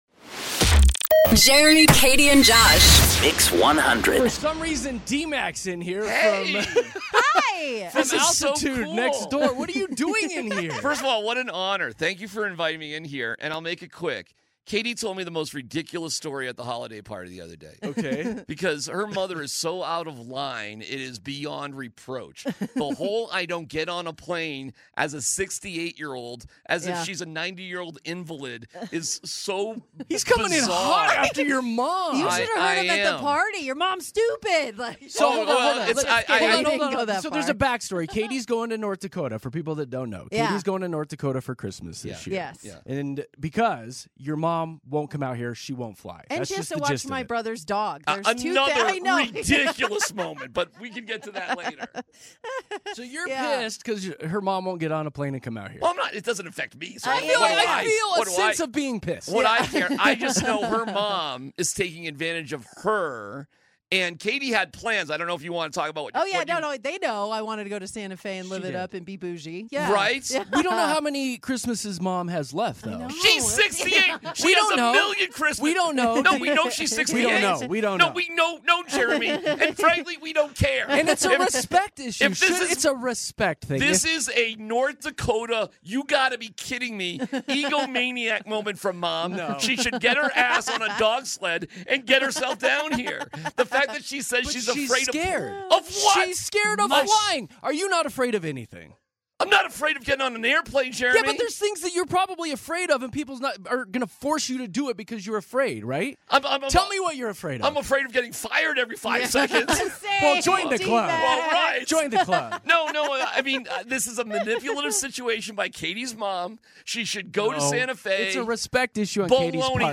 Tune in for this lively debate